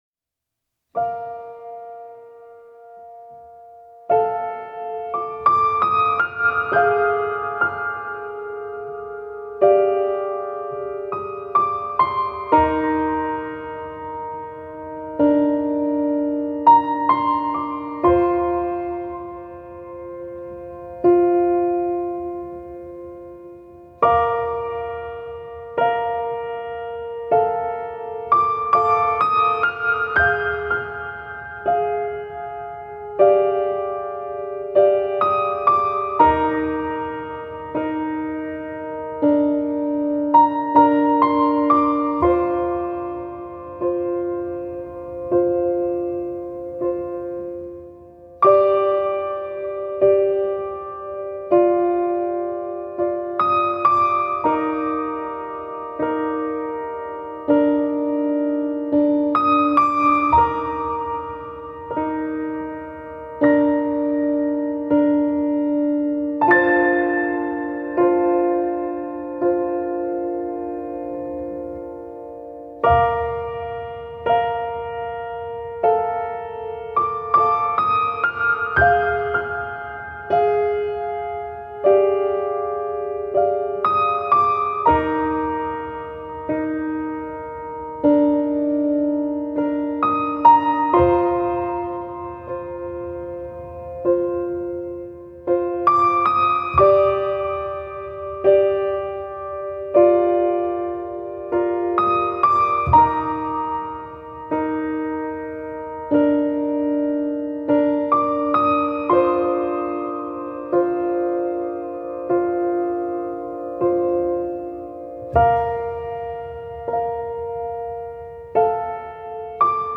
سبک آرامش بخش , پیانو , عصر جدید , موسیقی بی کلام